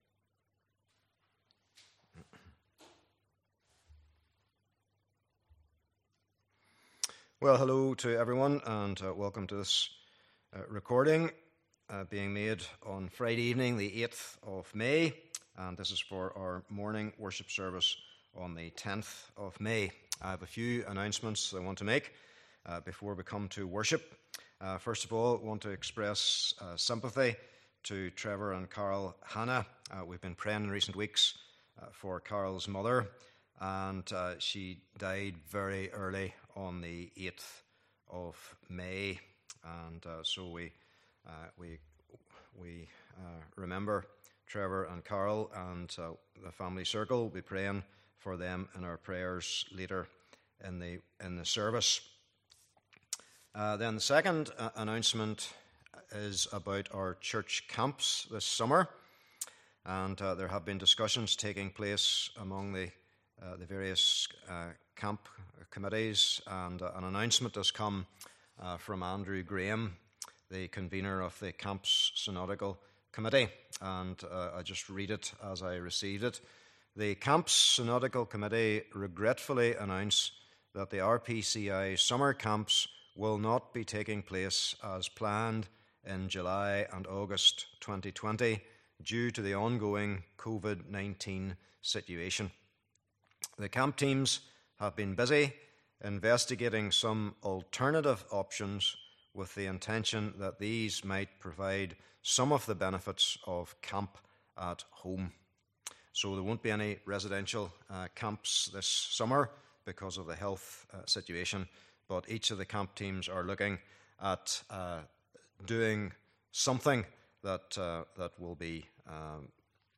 Passage: Exodus 20 :16 Service Type: Morning Service